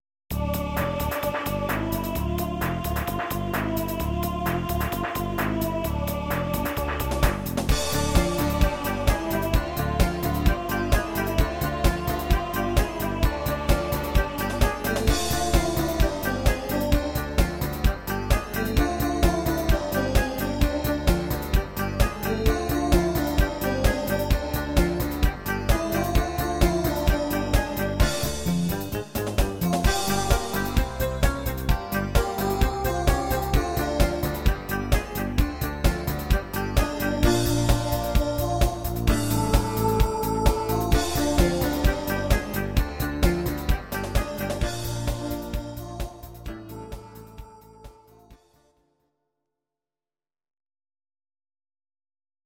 These are MP3 versions of our MIDI file catalogue.
dance mix